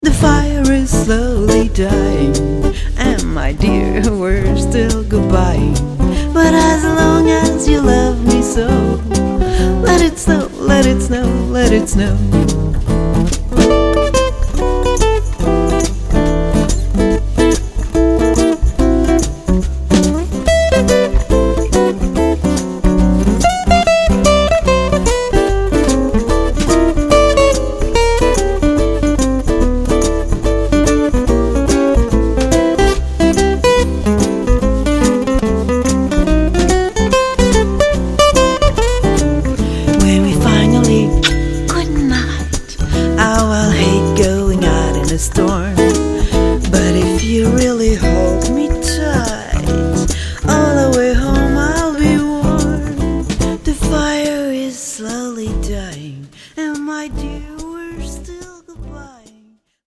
guitare cordes de nylon et percussions
contrebasse
guitare électrique